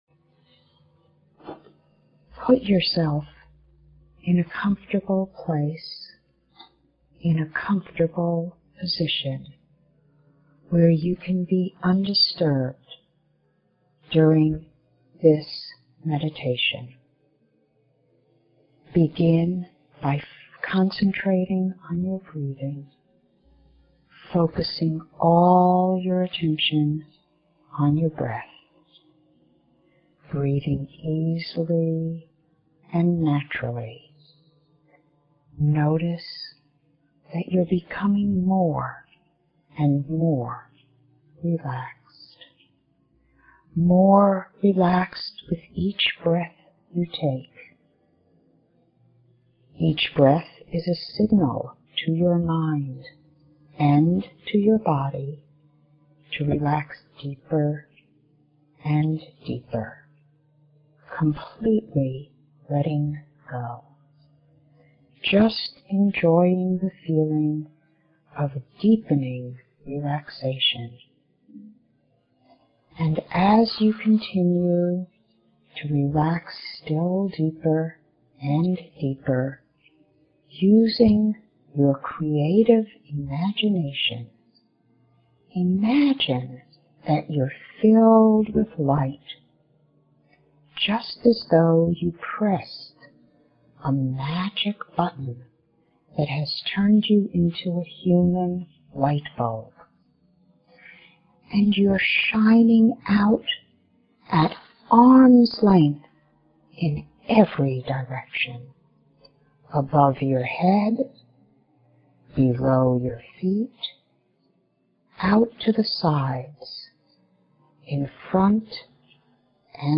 (This recording is intended to assist relaxation.